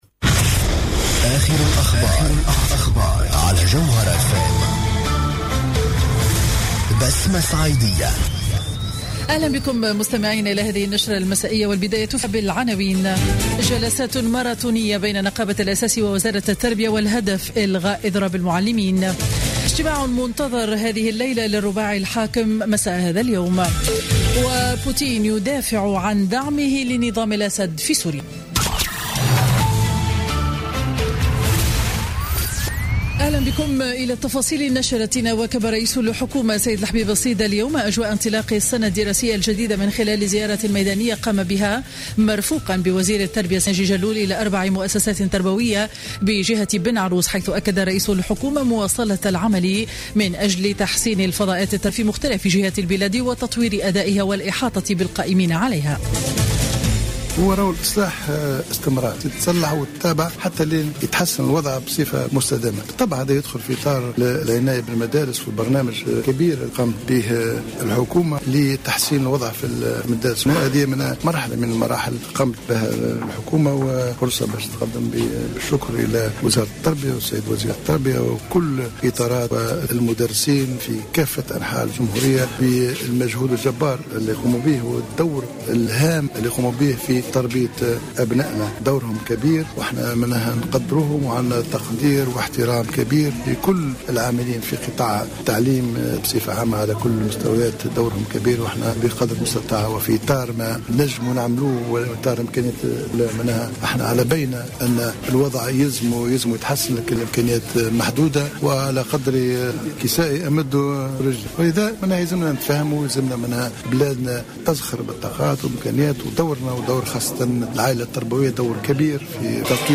نشرة أخبار السابعة مساء ليوم الثلاثاء 15 سبتمبر 2015